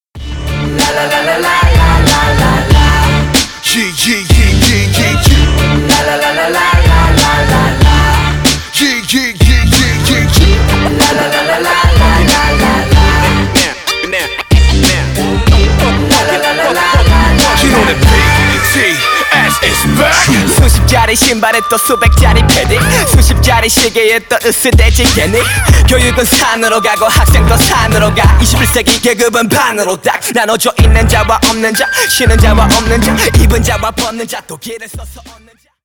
Поп Музыка